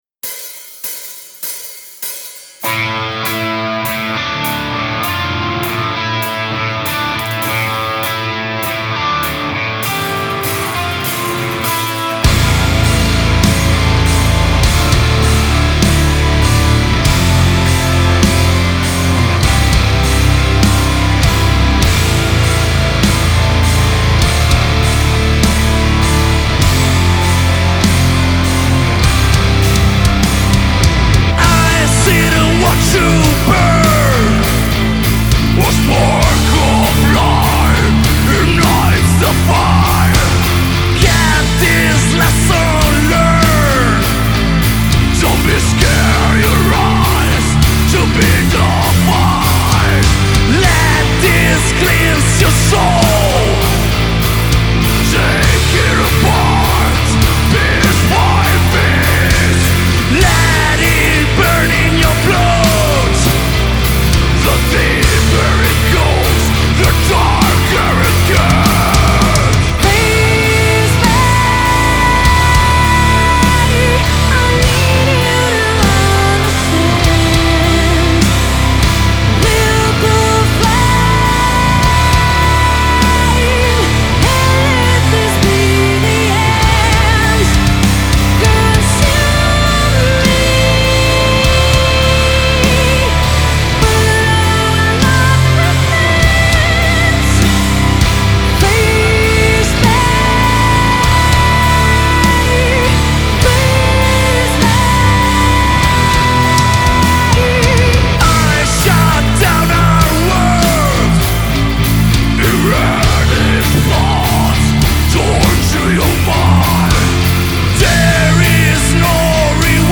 Genre : Rock, Hard Rock, Metal